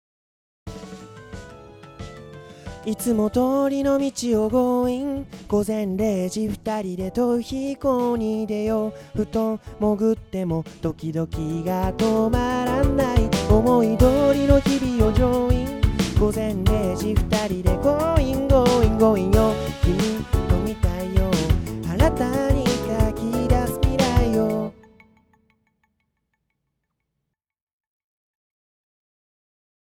低域の量感が少ないので、EQの限られたシチュエーションでは外しにくいかも。ハンドリングノイズがとても大きいので手持ちマイクでガンガン動く場合や、アコースティックDUOのシンガーは要注意。